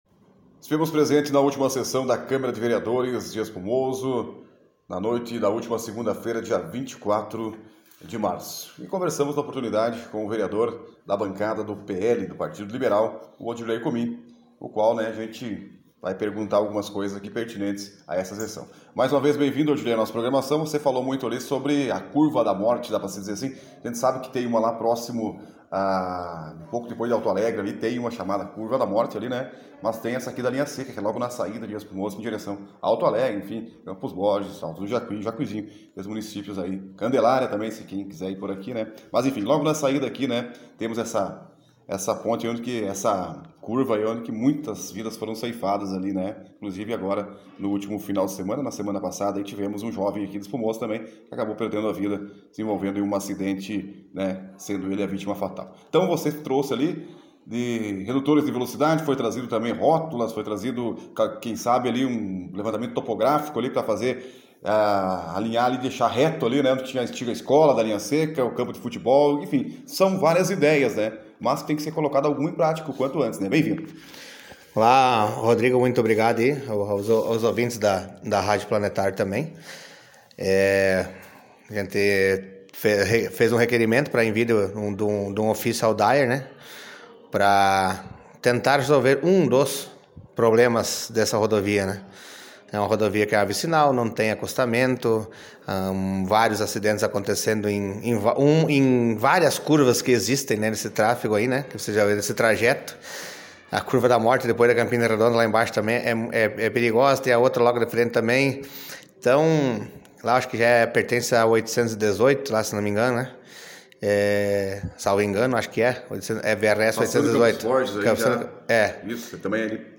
Vereador de Espumoso, Odirlei Comin, da bancada do PL fala a nossa reportagem
Além deste ofício, o vereador defende o melhoramento de outras vias. Confira na entrevista a seguir